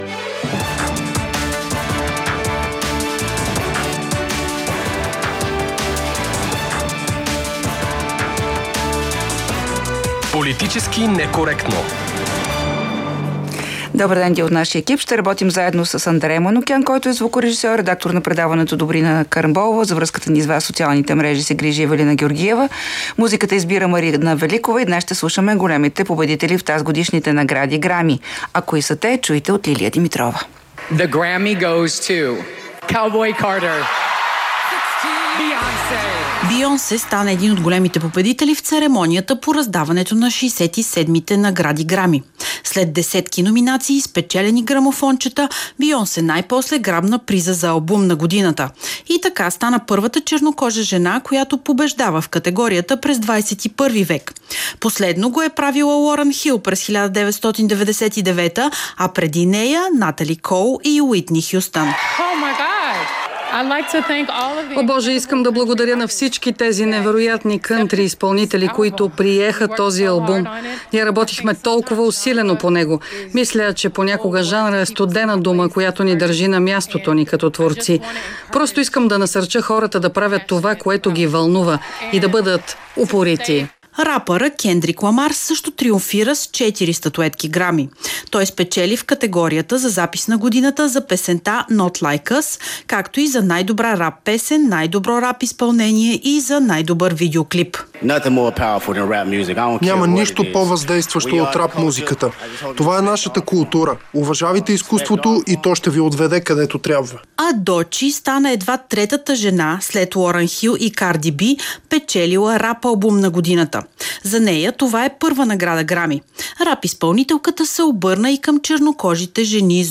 ▪ Гост в предаването е Лена Бориславова от Продължаваме Промяната – Демократична България.